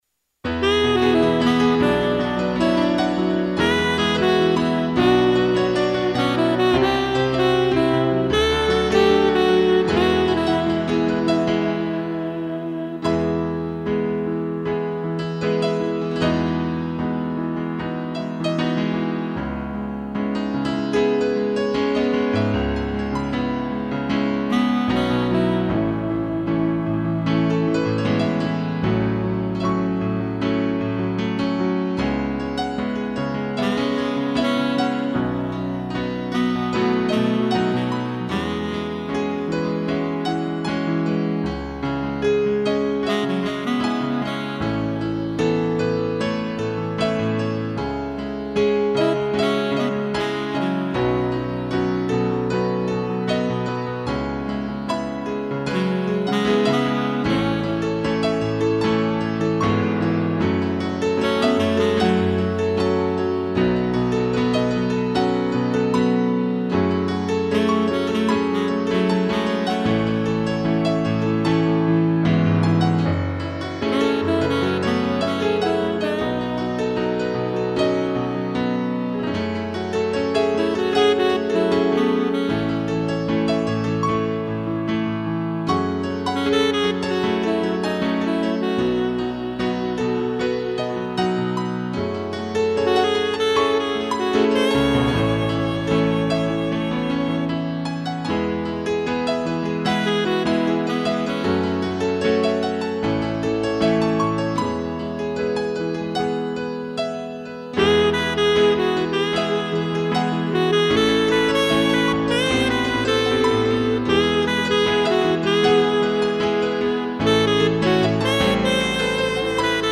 2 pianos e sax
(instrumental)